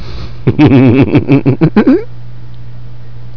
rsnicker.wav